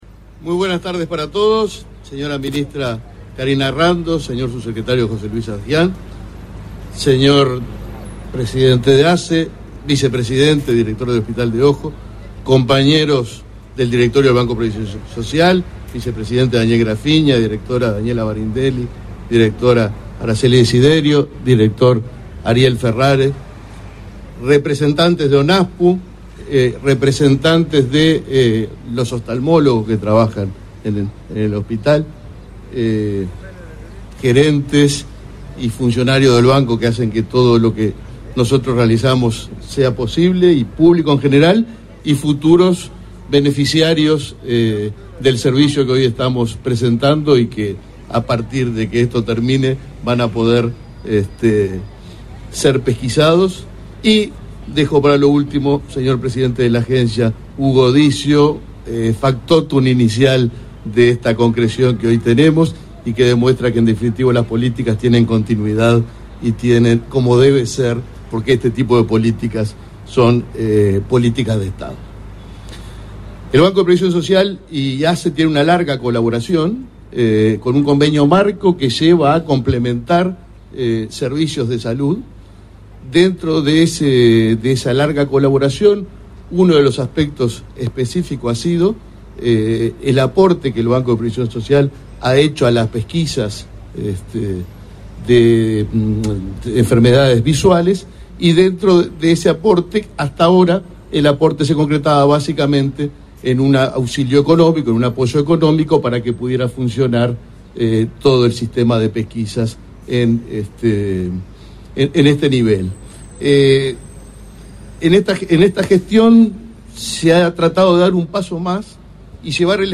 Conferencia de prensa por convenio entre BPS y ASSE
Conferencia de prensa por convenio entre BPS y ASSE 12/10/2023 Compartir Facebook X Copiar enlace WhatsApp LinkedIn El Banco de Previsión Social (BPS) y la Administración de los Servicios de Salud del Estado (ASSE) firmaron un convenio, este 12 de octubre, para la entrega en comodato al prestador de un ómnibus equipado como consultorio oftalmológico móvil para realizar pesquisas oftalmológicas en todo el país. Participaron en el evento el presidente del BPS, Alfredo Cabrera, y el titular de ASSE, Leonardo Cipriani.